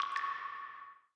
Slide open 2.wav